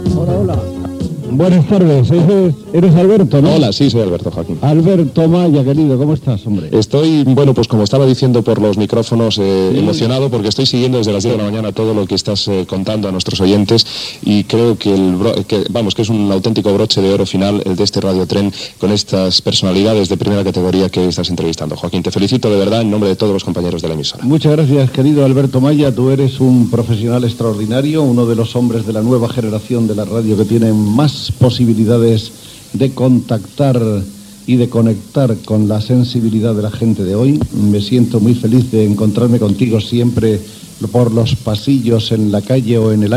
Inici de la conversa
Entreteniment